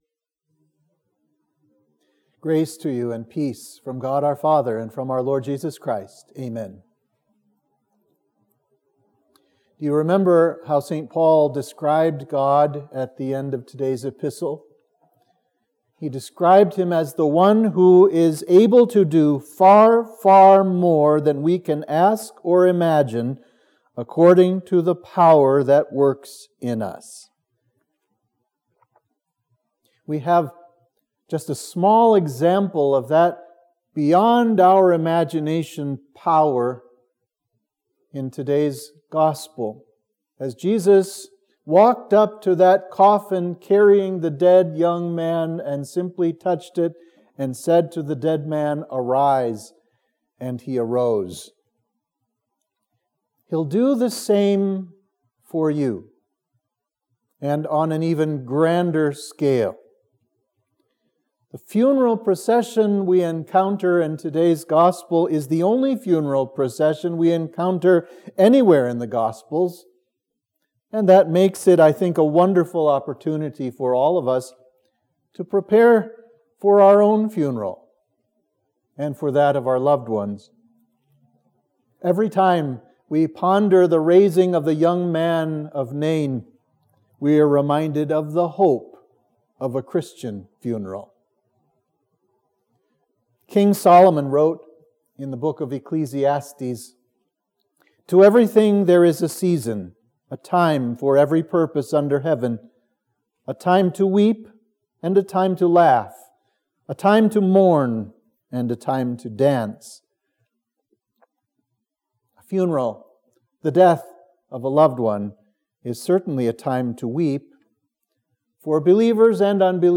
Sermon for Trinity 16